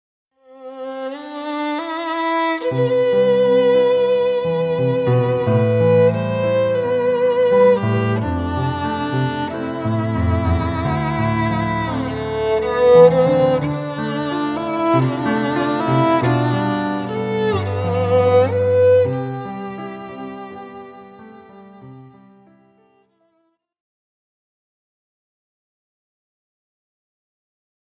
violin & piano duets